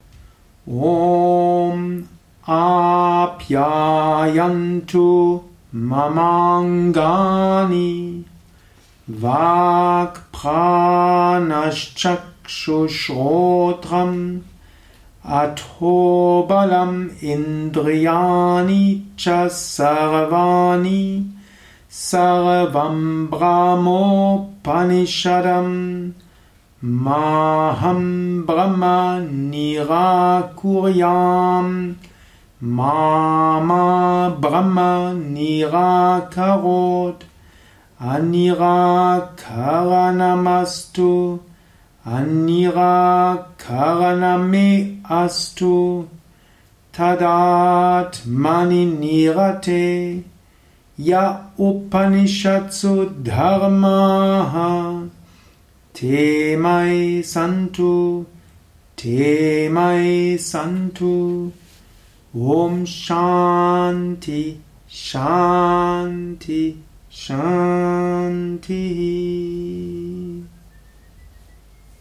Om Apyayantu Mamangani - Shanti Mantra ist ein Shanti Mantra
670-3-Om-Apyayantu-Mamangani-Shanti-Mantra-Strophe-3-langsam.mp3